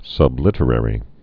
(sŭb-lĭtə-rĕrē)